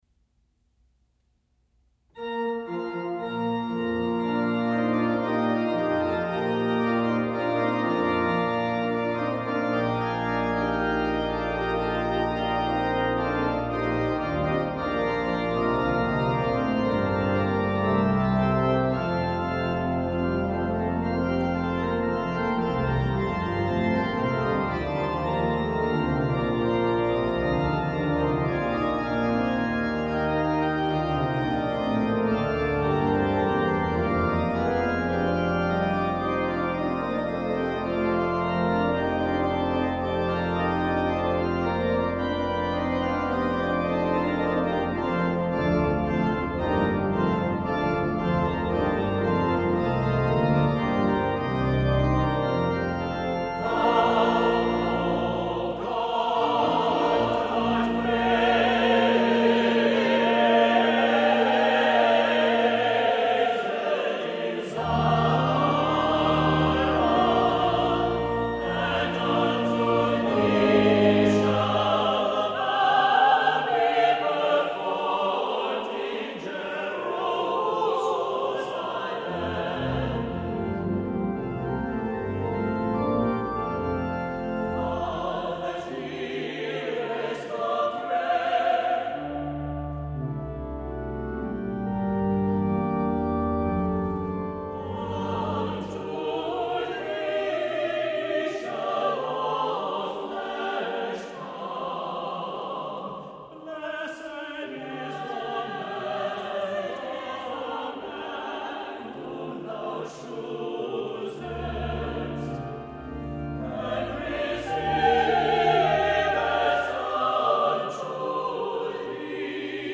• Music Type: Choral
• Voicing: SATB with divisi
• Accompaniment: Organ
• Liturgical Celebrations: Festive Anthems